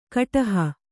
♪ kaṭaha